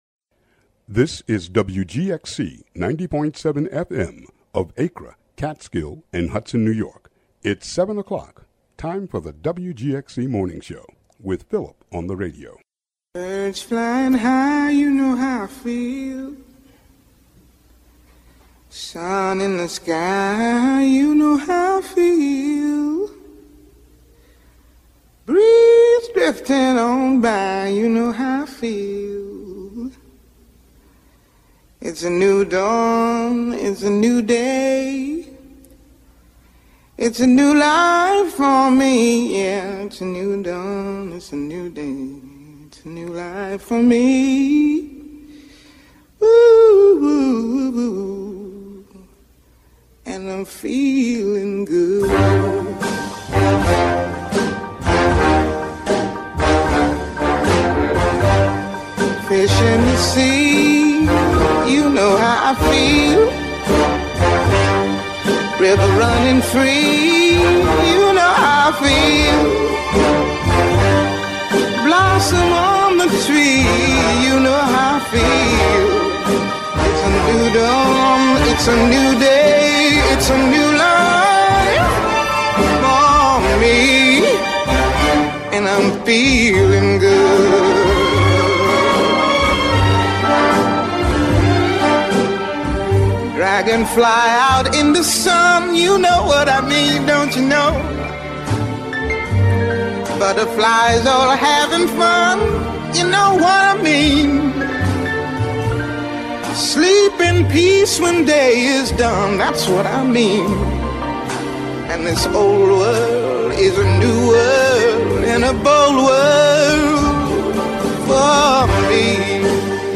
Various local interviews and music. (Audio)